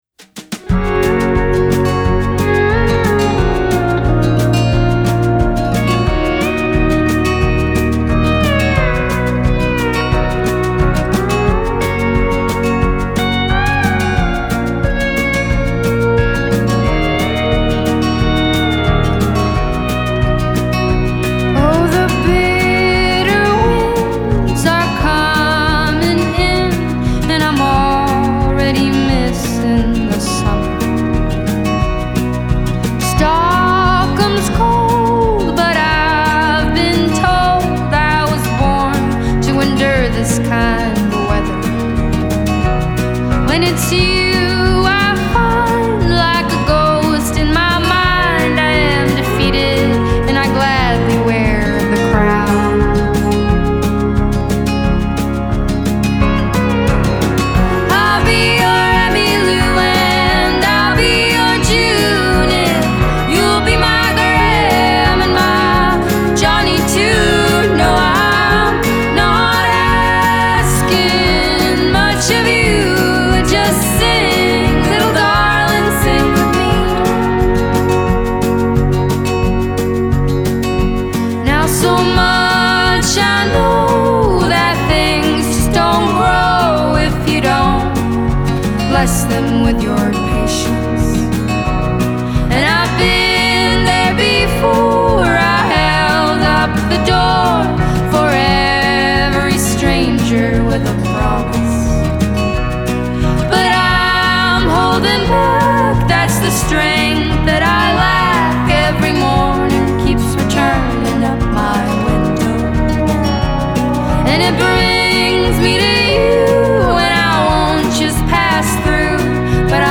Играют лёгкий акустический инди фолк-рок.
Genre: Folk / Indie / Sweden